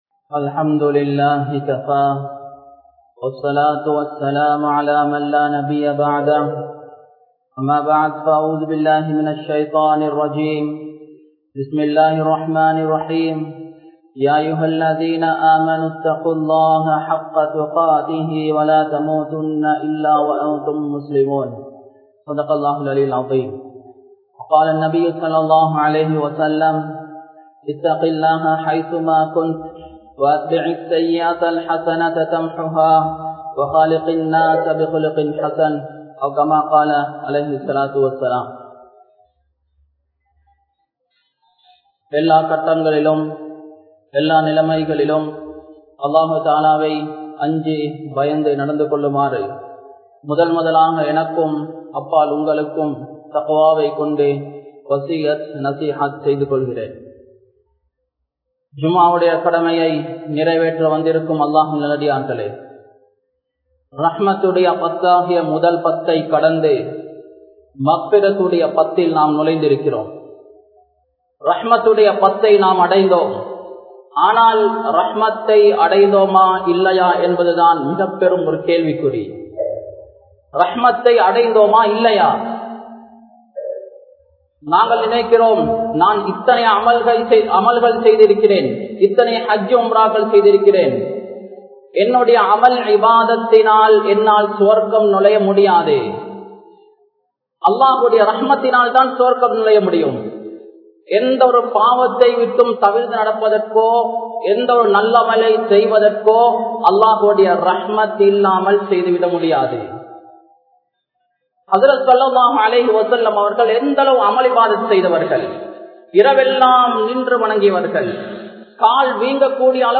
Allahvin Rahmaththai Adainthoma? (அல்லாஹ்வின் றஹ்மத்தை அடைந்தோமா?) | Audio Bayans | All Ceylon Muslim Youth Community | Addalaichenai